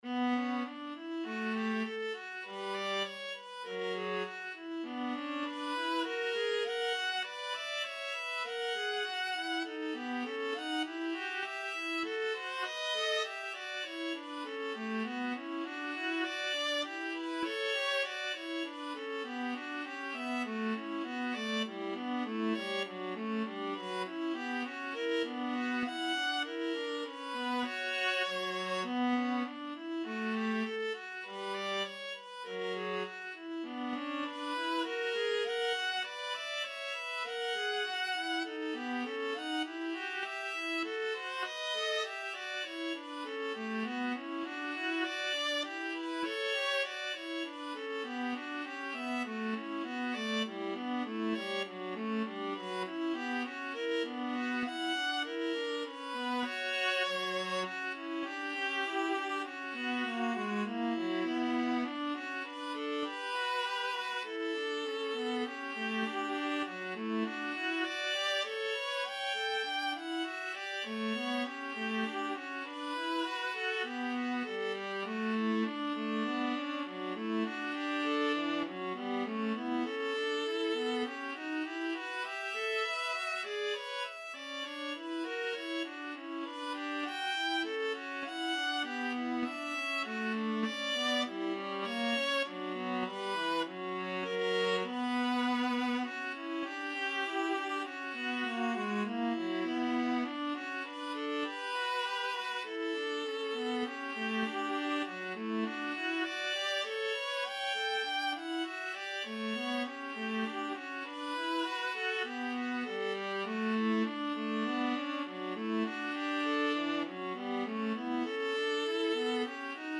B minor (Sounding Pitch) (View more B minor Music for Viola Duet )
2/2 (View more 2/2 Music)
Viola Duet  (View more Intermediate Viola Duet Music)
Classical (View more Classical Viola Duet Music)